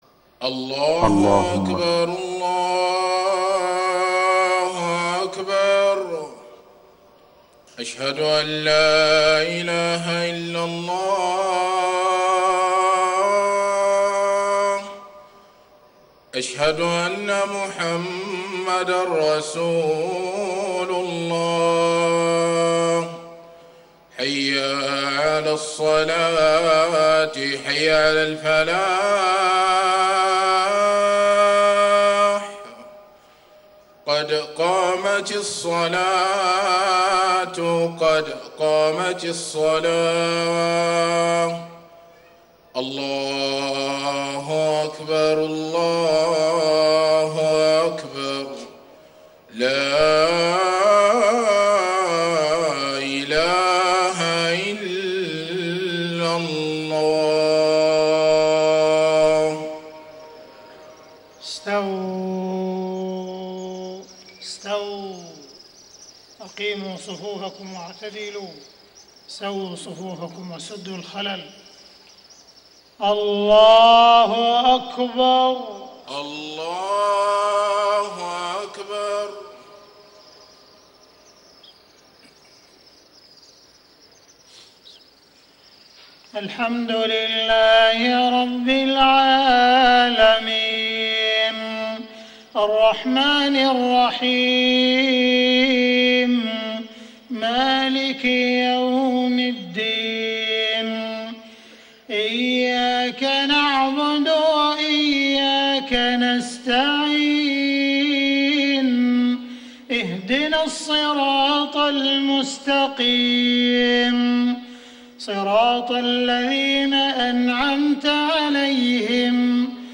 صلاة المغرب 4-4-1435هـ من سورتي الفجر و الكوثر > 1435 🕋 > الفروض - تلاوات الحرمين